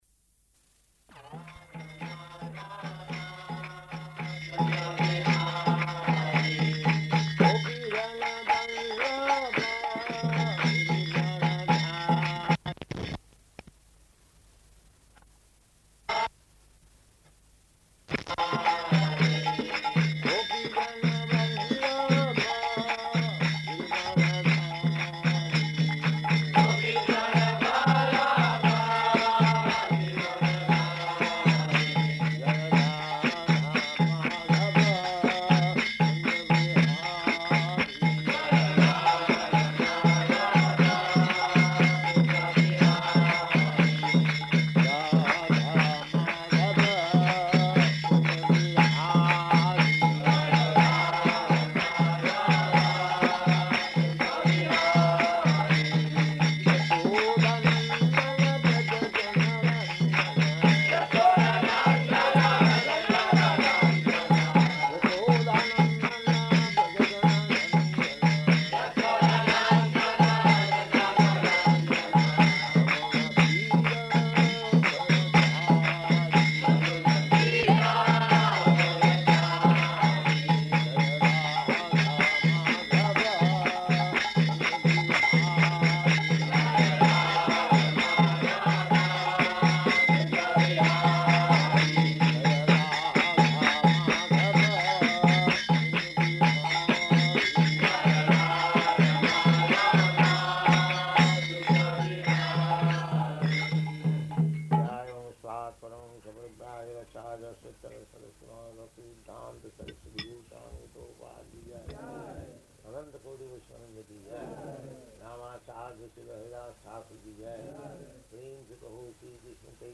Location: London
[Prabhupāda and devotees repeat]